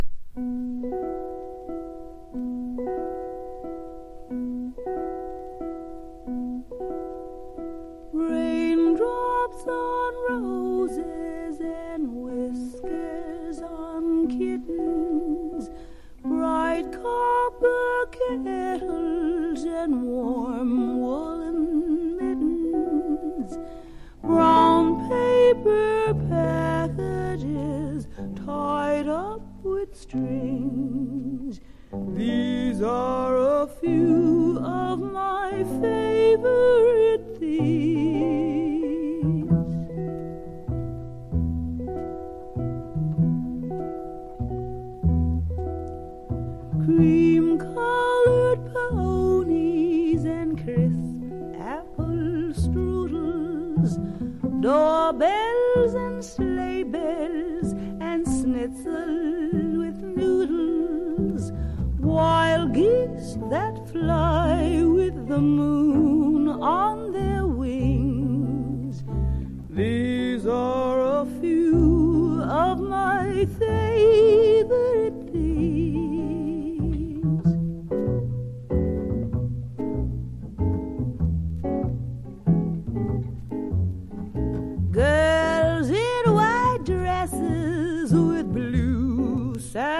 # VOCAL JAZZ# MODERN JAZZ